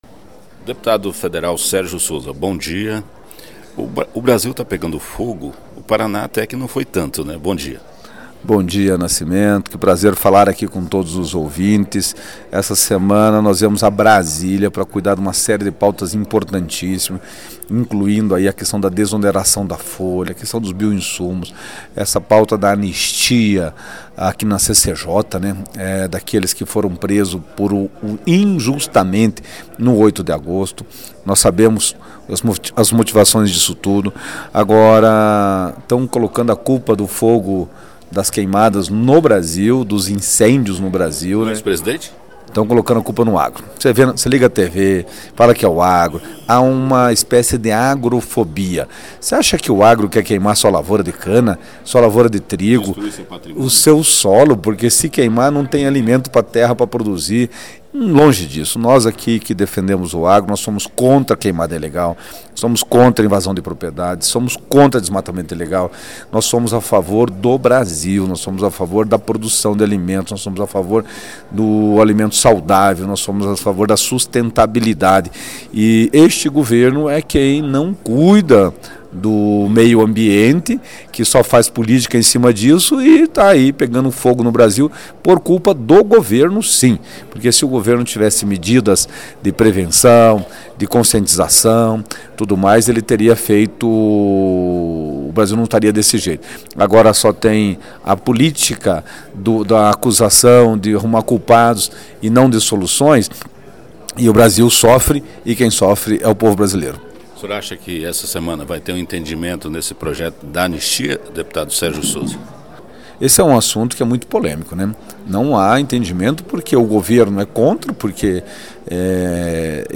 Sergio Souza (Dep. Federal/MDB) fala sobre as queimadas e pede anistia para golpistas de 8 de janeiro.